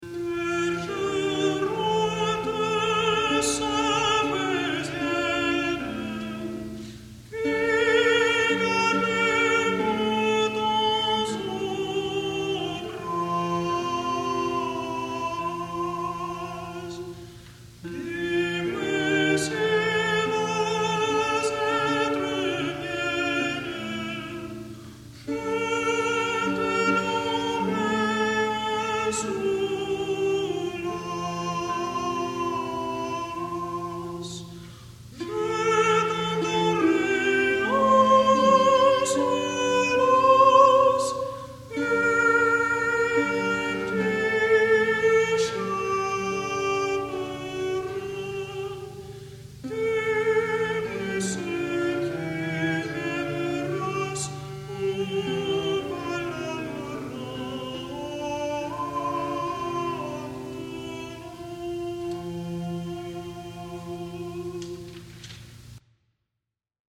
| Instrumental Ensemble, 'Cloth of Gold', session, 1972
Being textless invites interesting instrumentation .